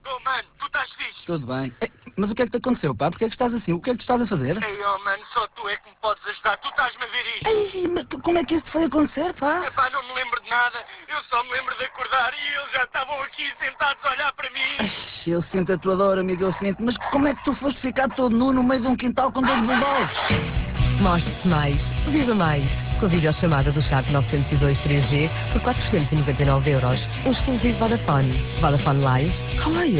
No passado dia 6 de Maio, estreou mais uma campanha a promover os telemóveis de 3.ª geração da marca Vodafone com dois spots diferentes de 30 segundos (